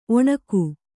♪ oṇaku